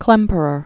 (klĕmpər-ər), Otto 1885-1973.